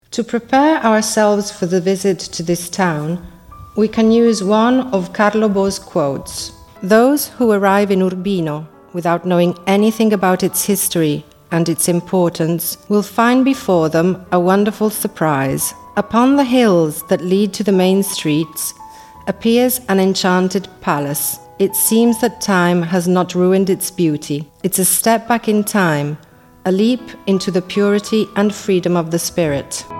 AUDIOGUIDA E SISTEMA MULTILINGUE